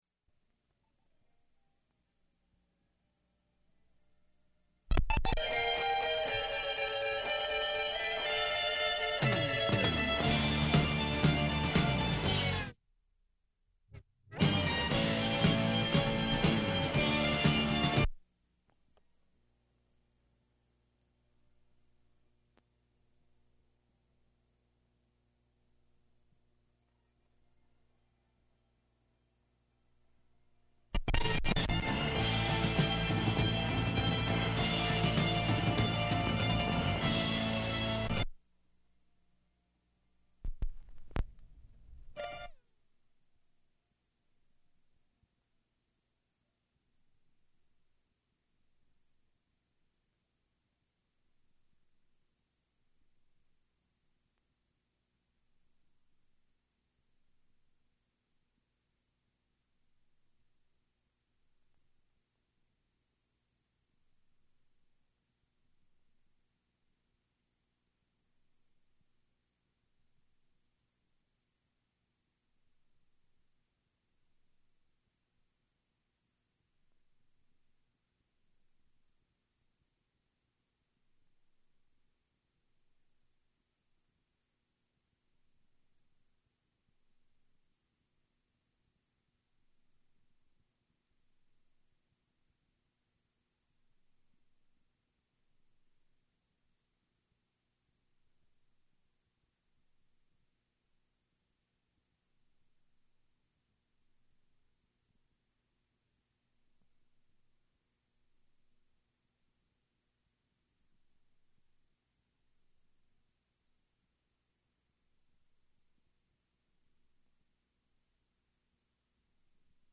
Music begins and halts abruptly
City Bus PSA
Caller asks about effects of another drought on San Luis Obispo